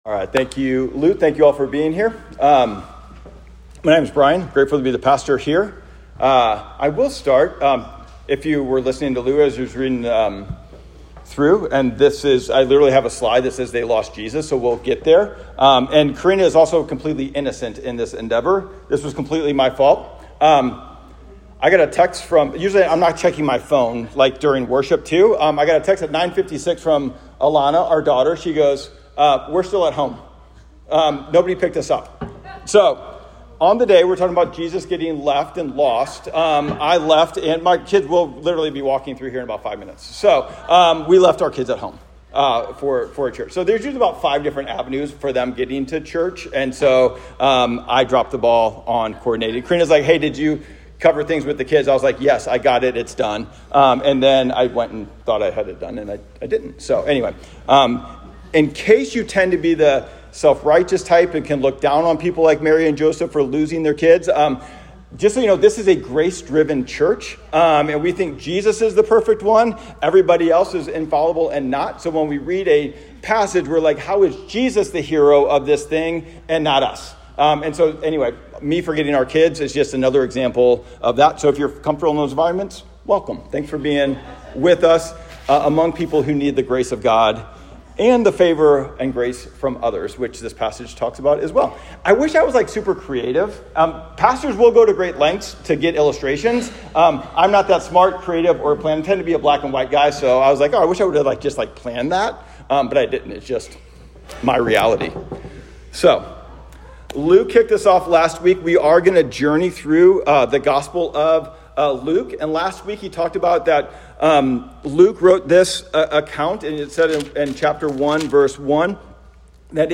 Luke-2.39-52_Sermon-Audio.m4a